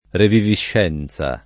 vai all'elenco alfabetico delle voci ingrandisci il carattere 100% rimpicciolisci il carattere stampa invia tramite posta elettronica codividi su Facebook reviviscenza [ revivišš $ n Z a ] (meno com. riviviscenza ) s. f.